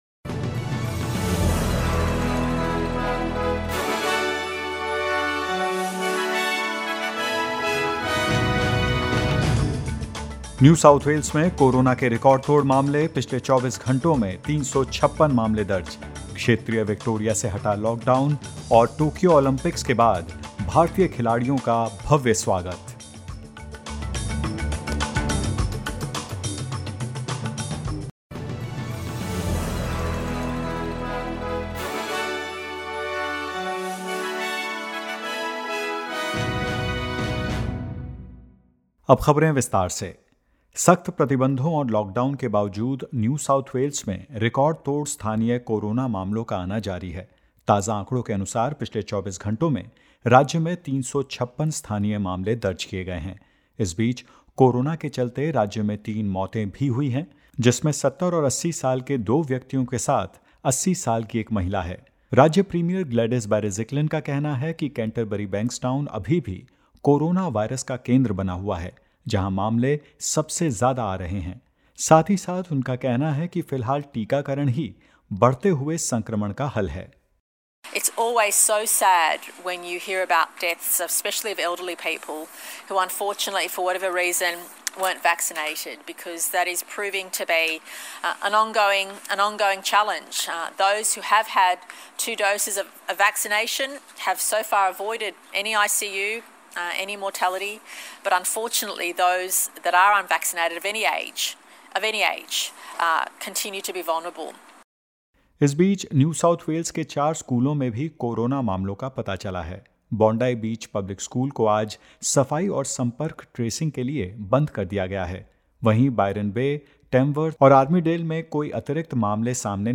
In this latest SBS Hindi News bulletin of Australia and India: The Therapeutic Goods Administration has given the Moderna COVID-19 vaccine provisional approval; Queensland’s new cases were in quarantine while infectious and more